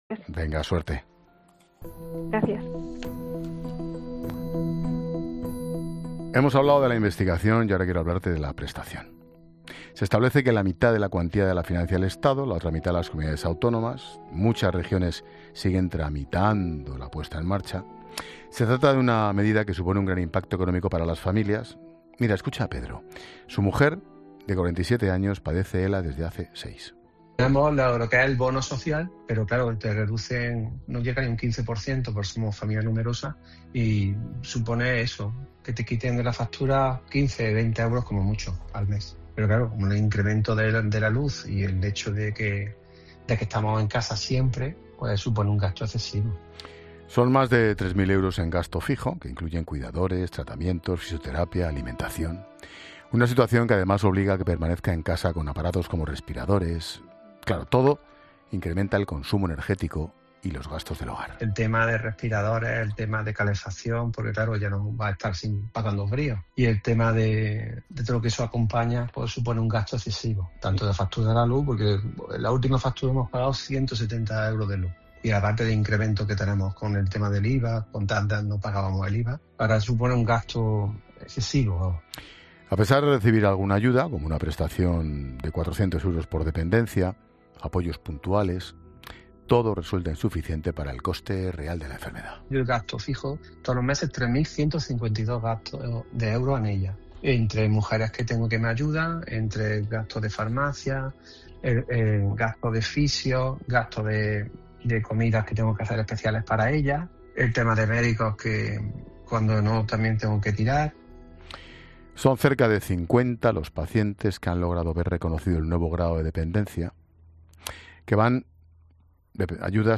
Expósito entrevista